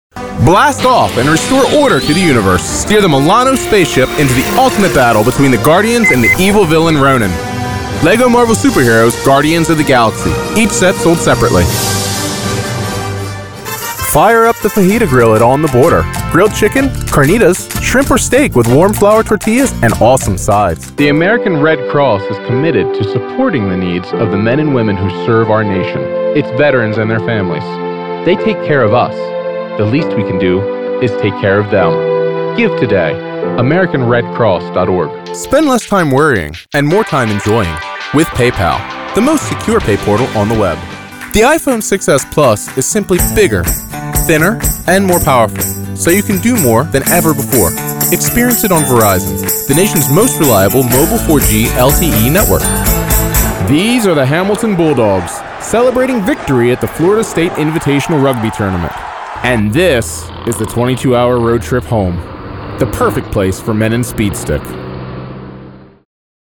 Voiceovers
Having a discrete soundbooth and professional setup, let Modern Eye Media work with you to create high quality productions.
MXL 770 Condenser Microphone, Focusrite Scarlett 2i4 Audio Interface, DBX 286S Preamp/Processor, Adobe Audition Creative Cloud.